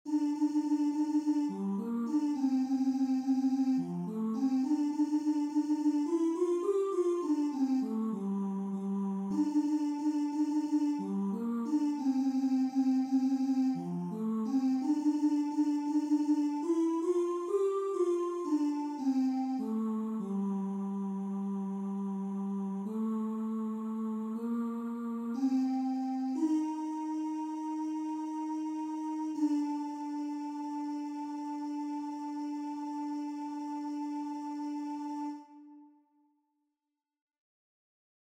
Key written in: G Dorian
Each recording below is single part only.